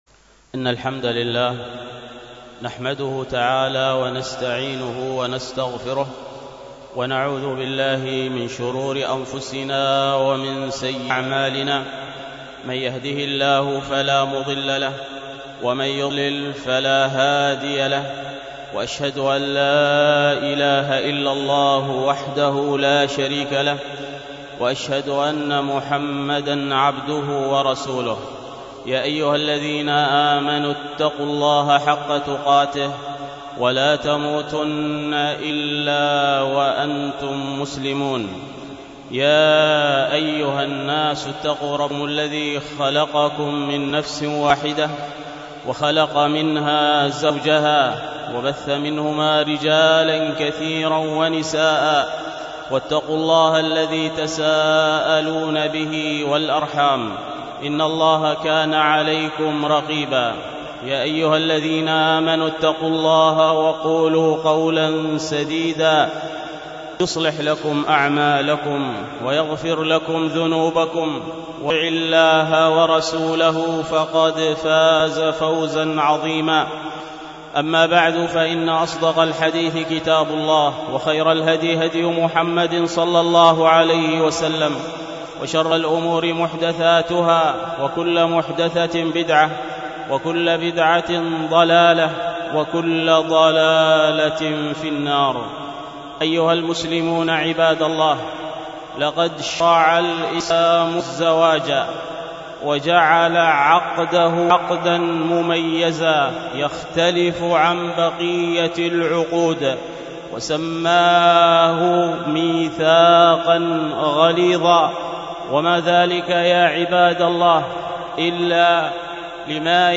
الخطبة